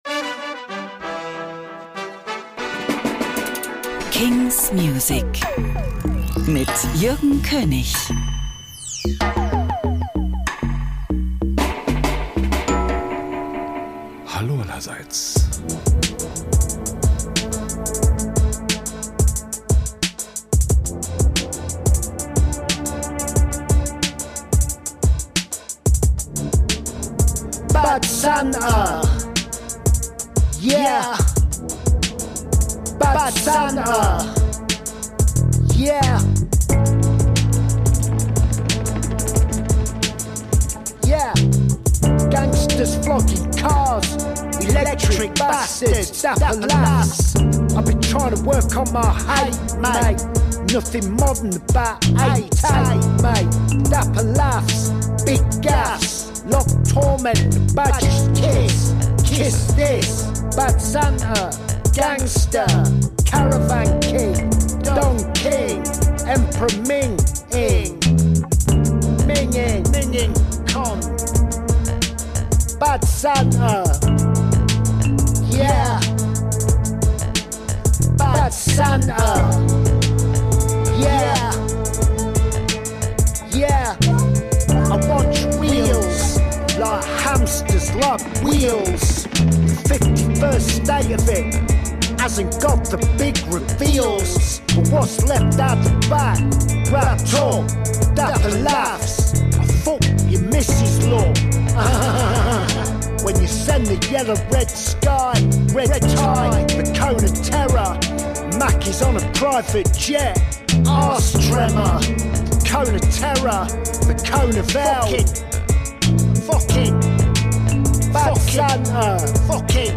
great, new indie & alternative releases